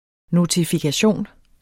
notifikation substantiv, fælleskøn Bøjning -en, -er, -erne Udtale [ notifigaˈɕoˀn ] Betydninger 1.